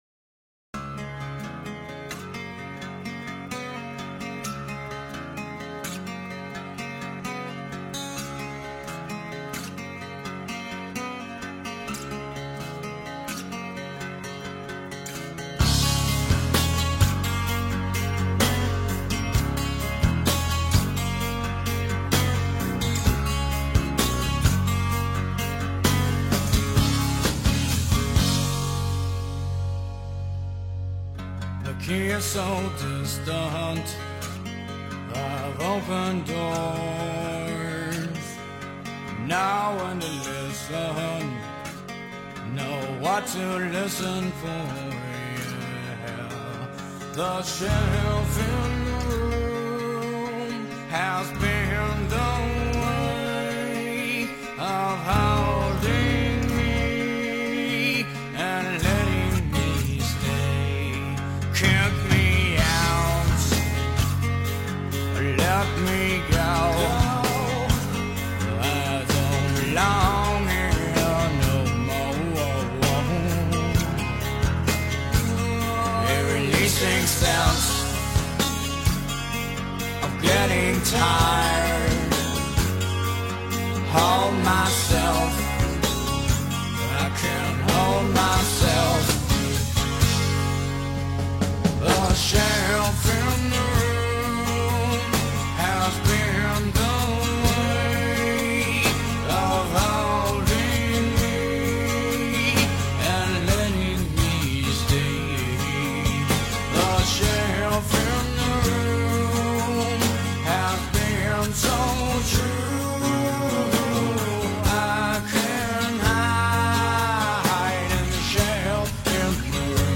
Post-Grunge, Acoustic Rock, Alternative Rock